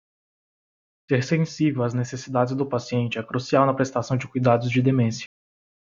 Pronounced as (IPA)
/sẽˈsi.vew/